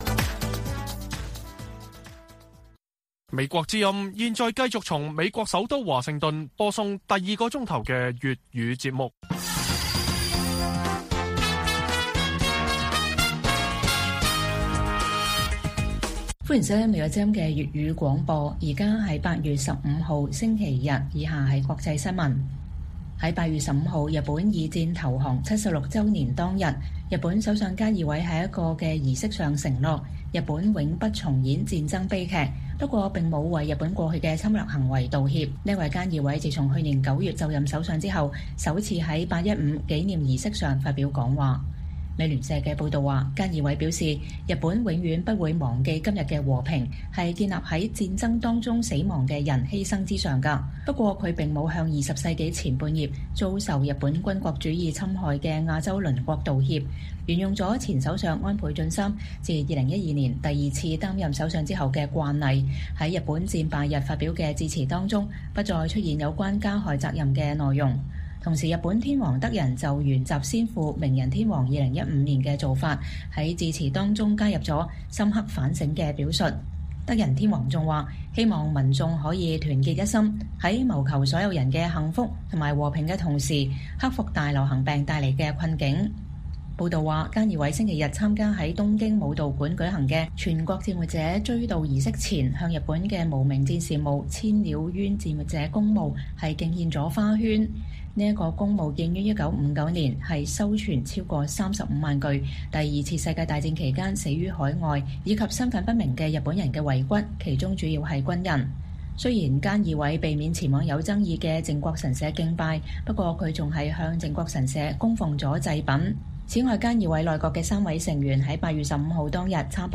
粵語新聞 晚上10-11點: 日相菅義偉承諾永不重演戰爭悲劇